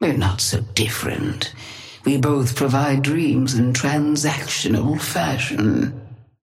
Sapphire Flame voice line - We're not so different.
Patron_female_ally_wraith_start_07.mp3